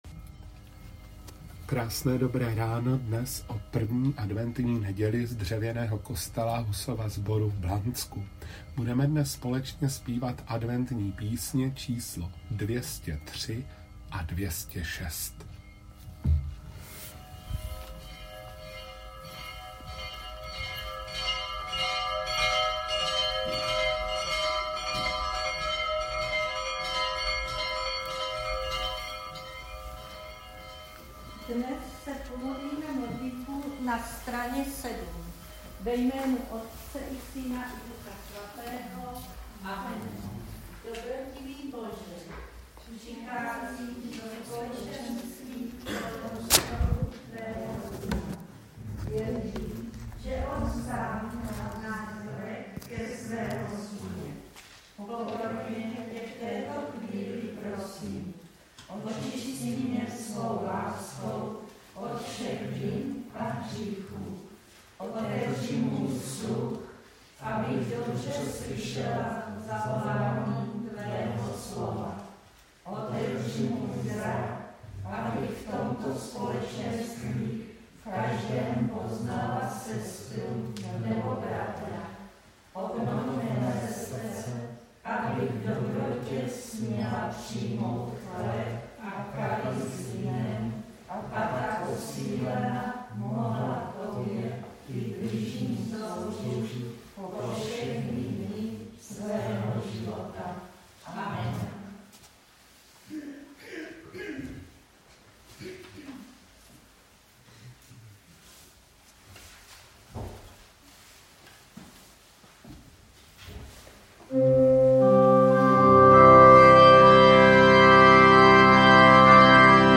1. neděle adventní - přehled bohoslužeb
Video a audio záznamy bohoslužeb ze sborů Církve československé husitské.
blansko_1a_2020.mp3